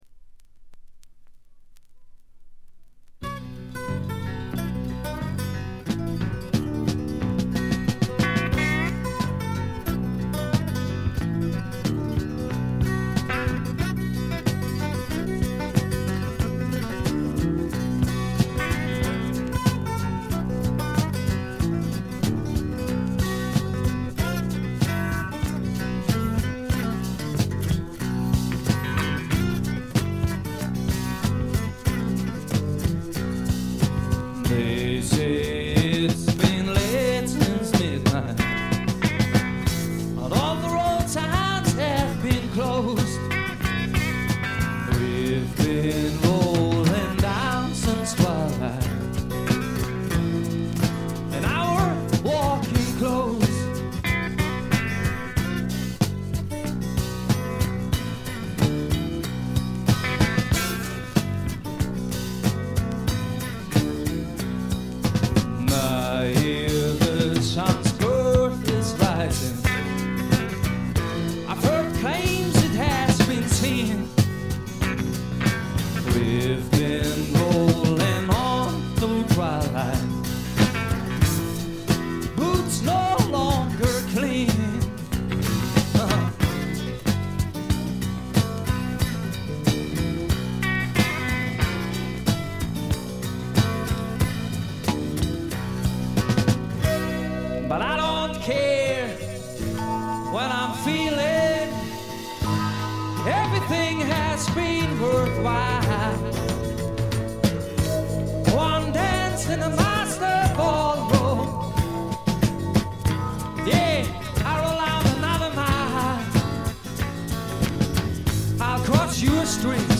静音部で軽微なチリプチ。
ドイツにひっそりと残るフォーク・ロック、サイケ／アシッド・フォークの名盤。
試聴曲は現品からの取り込み音源です。